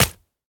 Minecraft Version Minecraft Version 25w18a Latest Release | Latest Snapshot 25w18a / assets / minecraft / sounds / item / trident / pierce1.ogg Compare With Compare With Latest Release | Latest Snapshot
pierce1.ogg